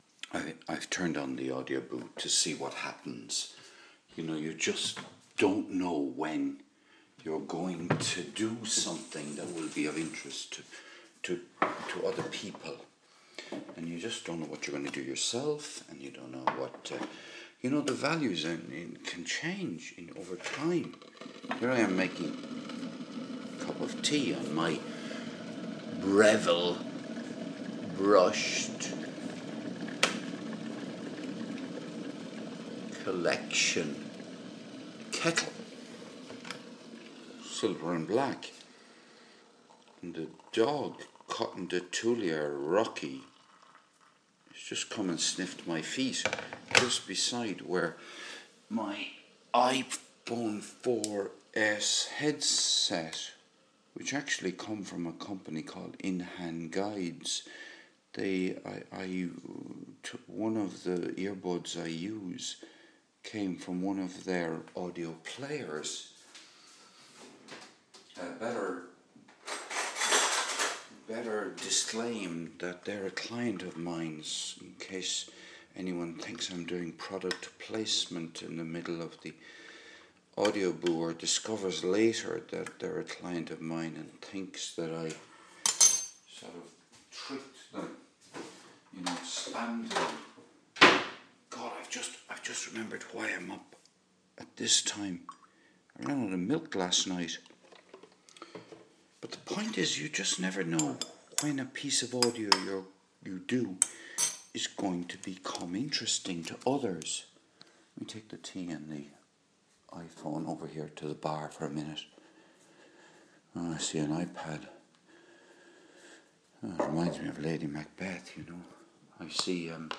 At one level this is the sound of a man making his first cup of tea... At another level this is a fragment of a philosophy of life...